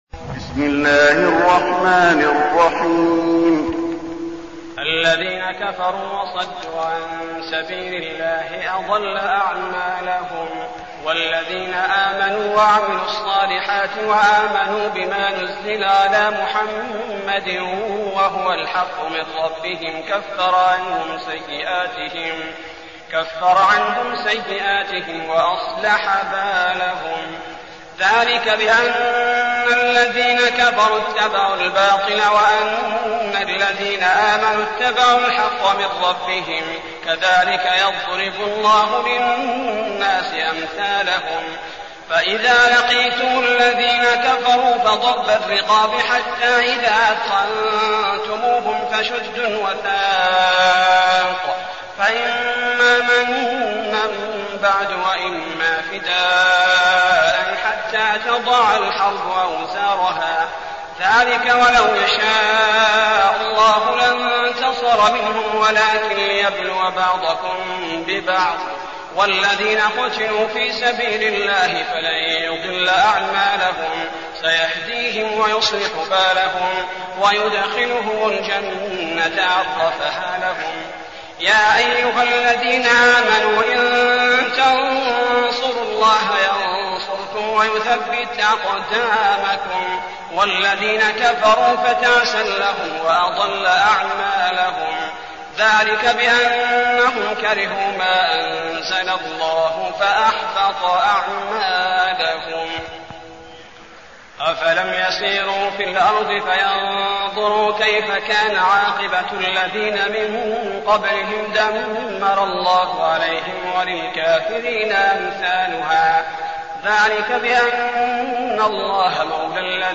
المكان: المسجد النبوي محمد